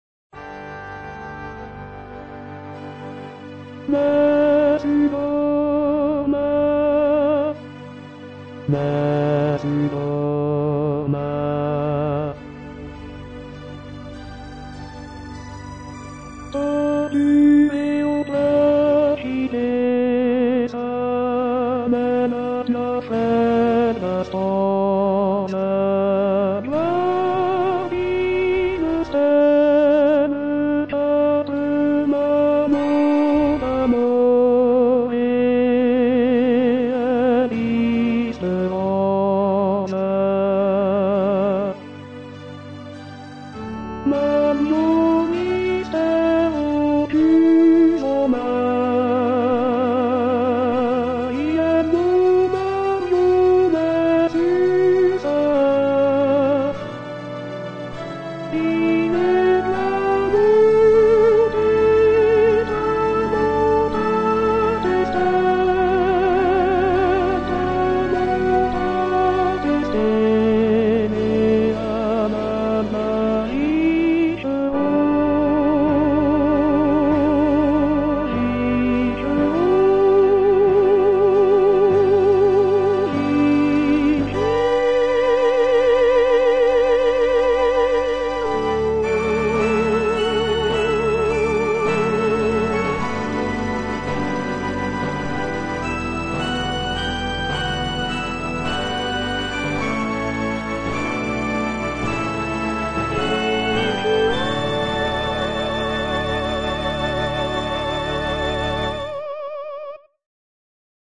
One of my virtual singers
sings Nessun dorma. Not only does he nail the B, he adds a D at the end and outlasts the orchestra.